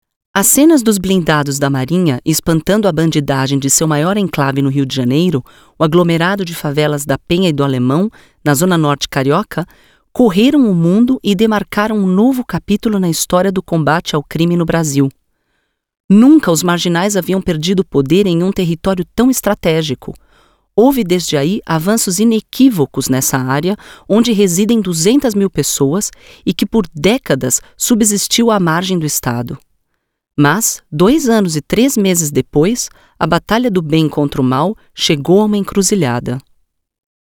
Kräftige, ausdrucksvolle Stimme.
Sprechprobe: Industrie (Muttersprache):
Strong, expressive voice.